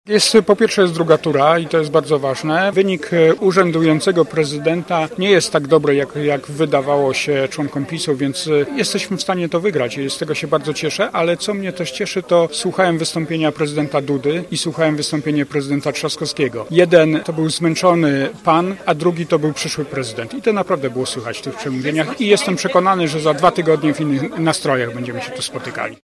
Wieczór wyborczy zorganizowany przez Koalicję Obywatelską zgromadził w jednym z gorzowskich klubów zwolenników i sympatyków kandydata na prezydenta RP Rafała Trzaskowskiego.
Mówi Krystyna Sibińska posłanka na Sejm RP.